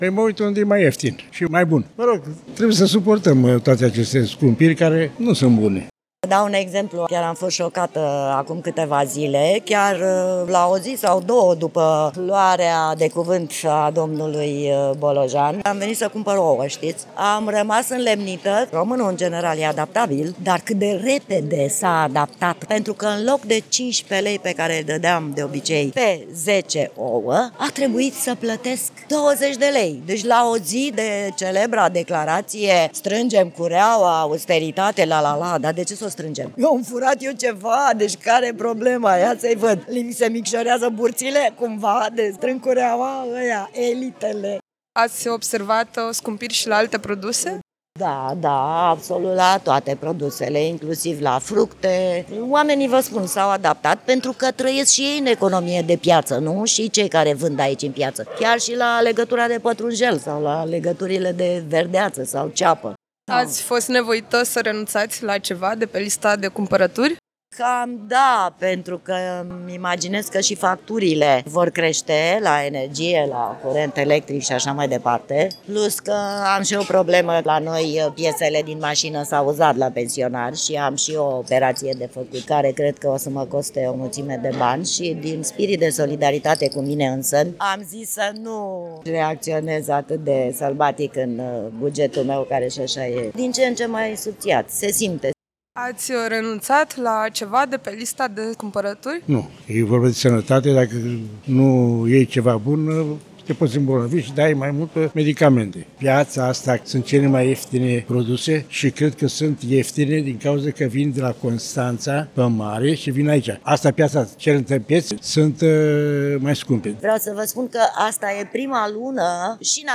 În piața Sfântul Gheorghe din Tulcea, cumpărătorii se gândesc de două ori înainte să bage mâna în buzunar.